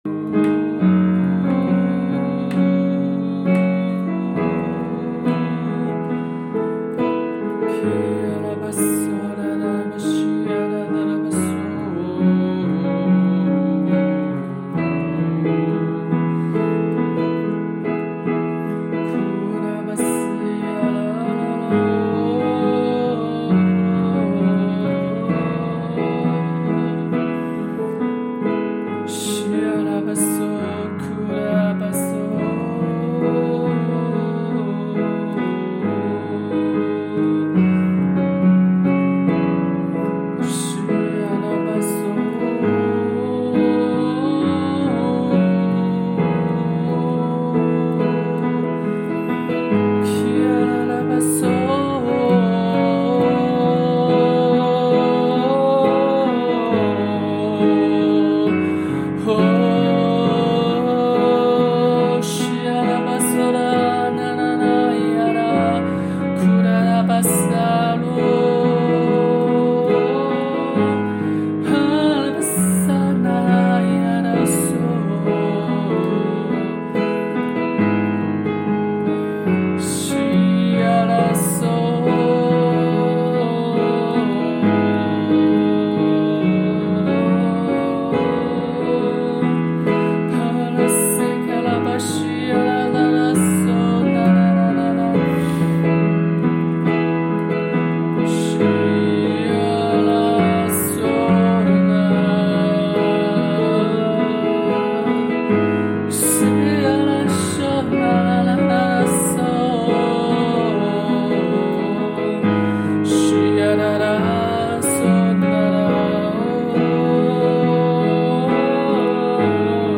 启示性祷告：